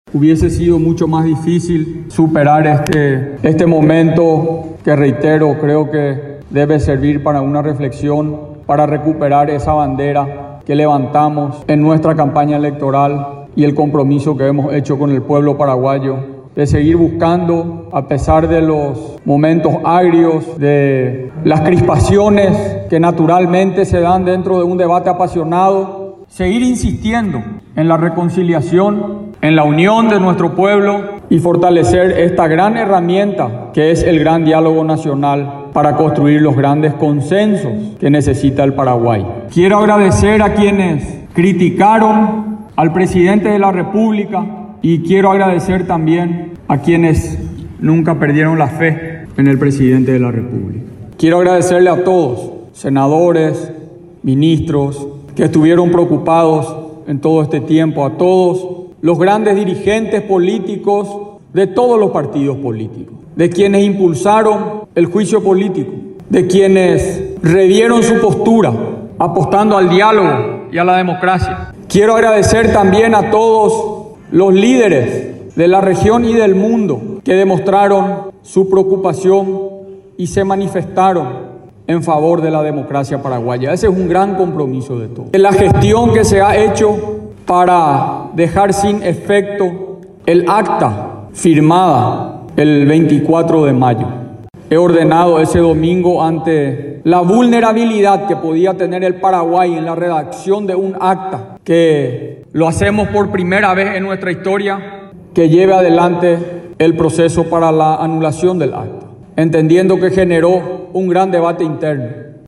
El mandatario paraguayo Mario Abdo Benítez, hizo un llamado al diálogo y a la reflexión a todos los líderes políticos del Paraguay.
Durante el discurso de este jueves, realizado en Palacio de Gobierno, pidió disculpas si se ha equivocado.